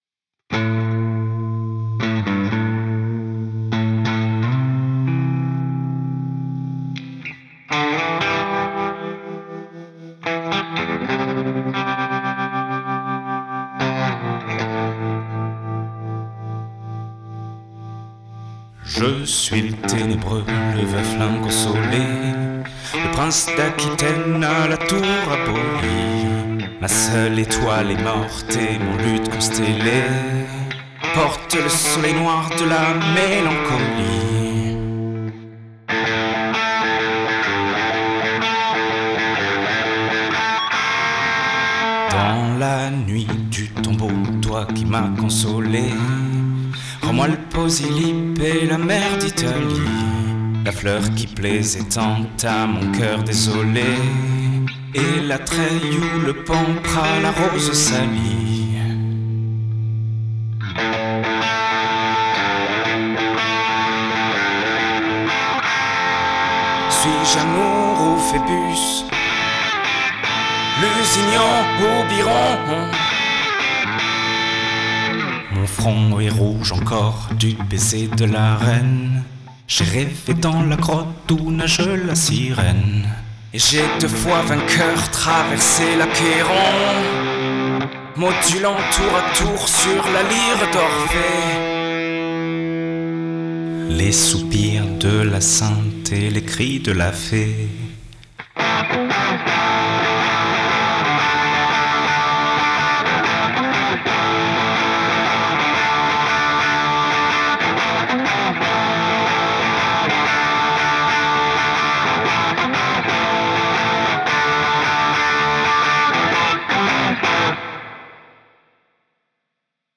voix et guitare
Guitare additionnelle